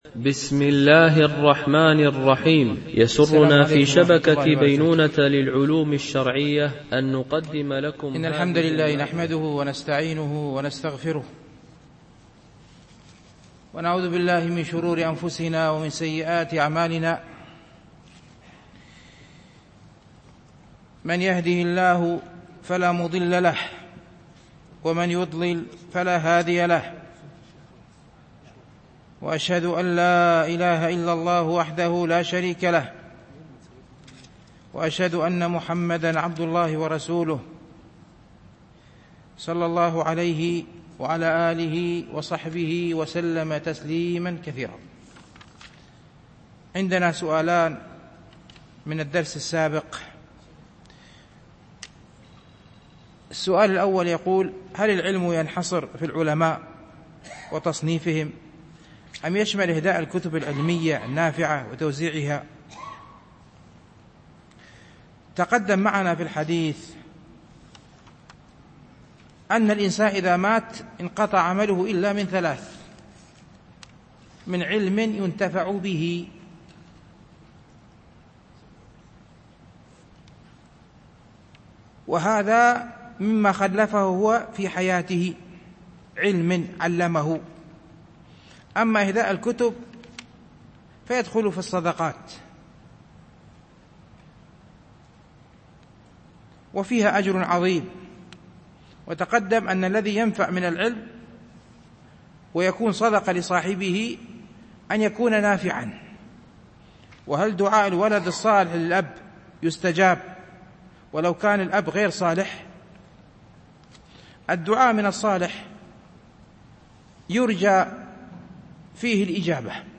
شرح رياض الصالحين – الدرس 249 ( الحديث 959 – 961 )